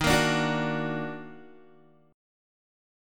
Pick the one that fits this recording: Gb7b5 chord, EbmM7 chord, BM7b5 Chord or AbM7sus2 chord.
EbmM7 chord